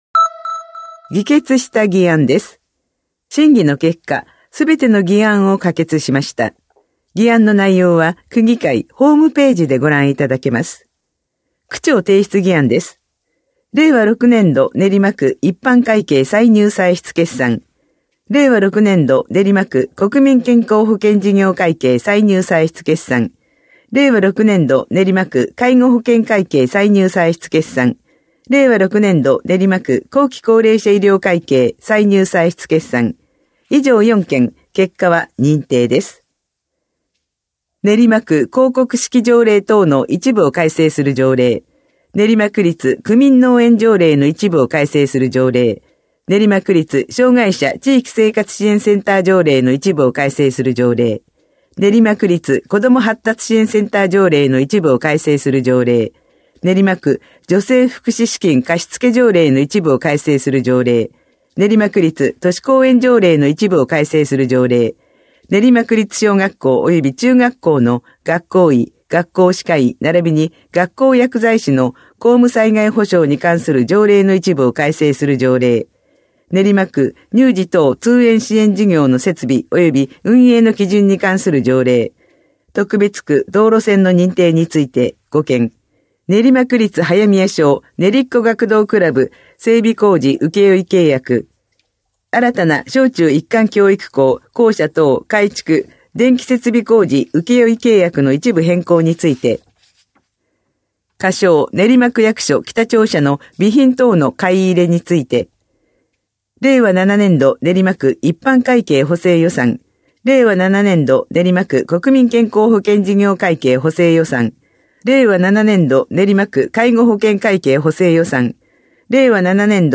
声の区議会だより（音声データ）
練馬区議会では、目の不自由な方のために、デイジーによる「声の区議会だより」を発行しています。